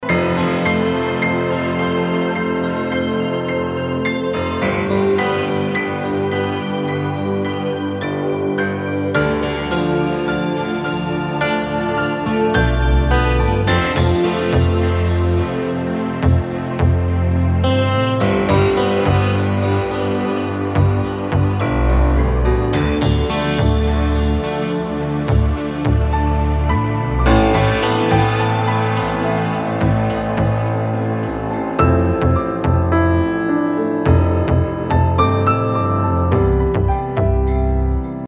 آهنگ موبایل ملایم و جذاب(بی کلام)